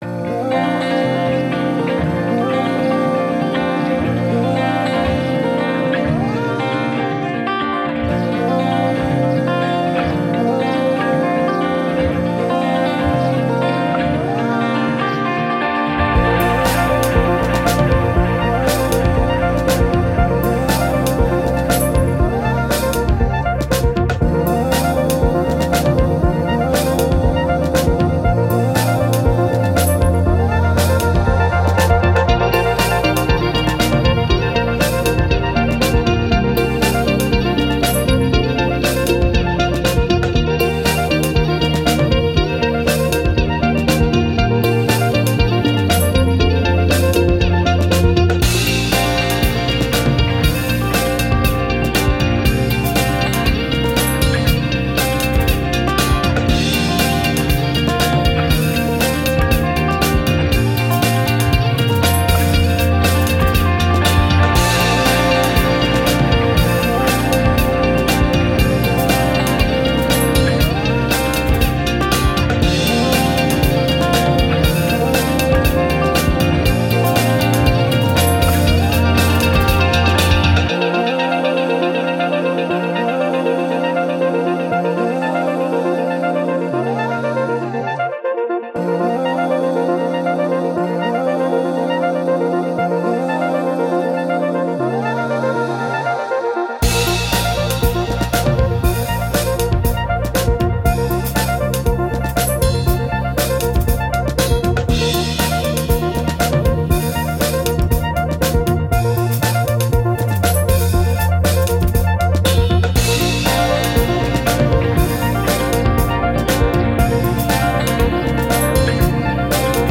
Ambient, Electronic, Positive, Quirky, Cheeky